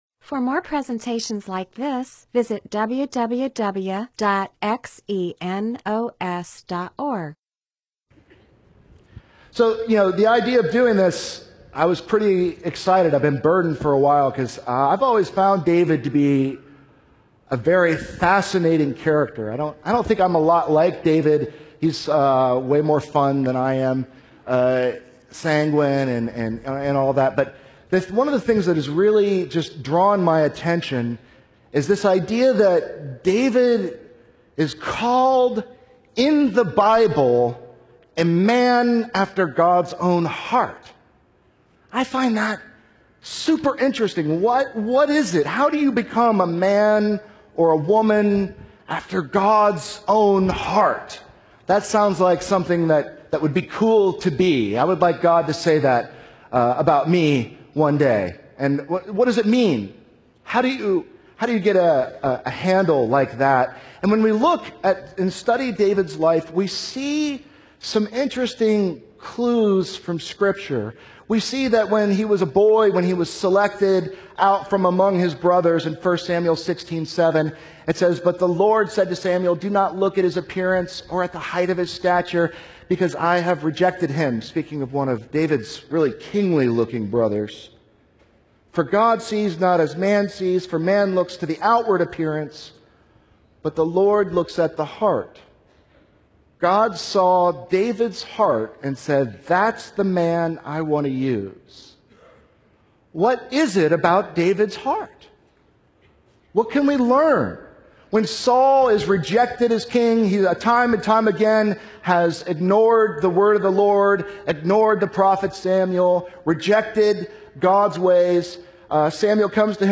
MP4/M4A audio recording of a Bible teaching/sermon/presentation about Psalms 51.